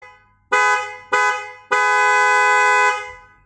BLUE TONEのヨーロピアンホーンは安価ですが、非常に心地よい音で、音量も大きめで大人な印象で満足してます。